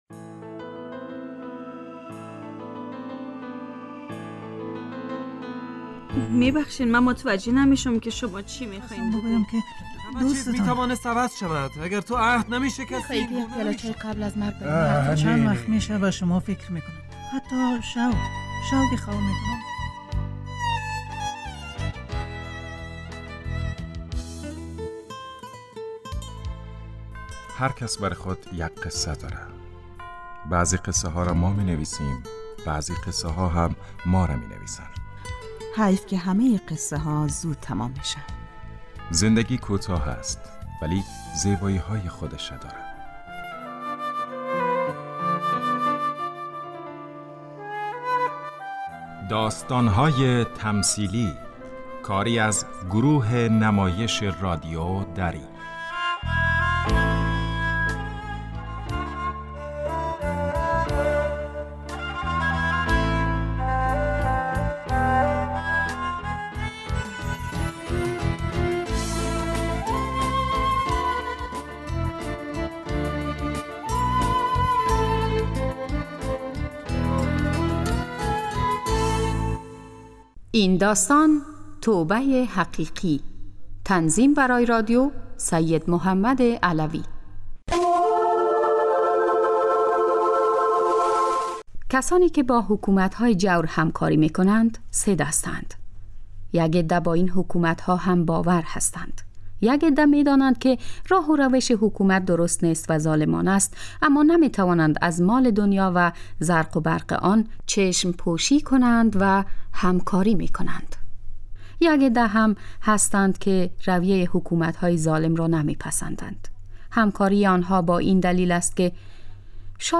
داستان تمثیلی - توبه ی حقیقی